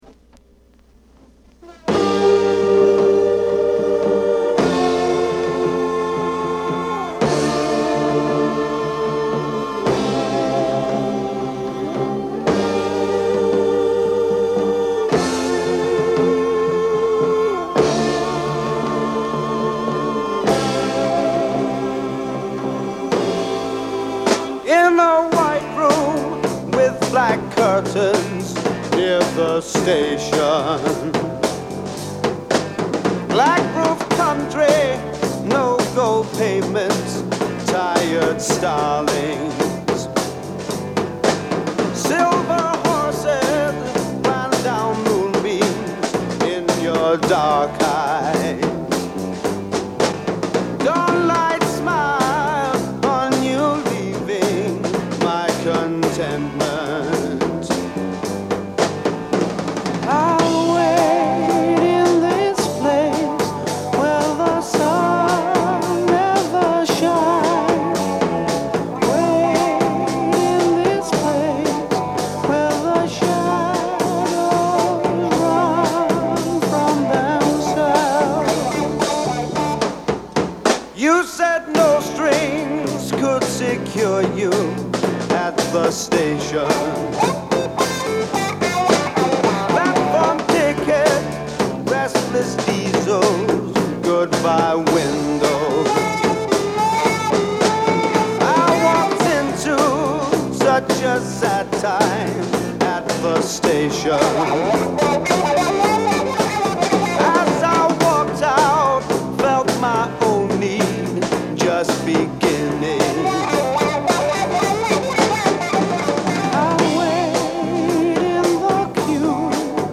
盤は薄い擦れや僅かですが音に影響がある傷がいくつかある、使用感が感じられる状態です。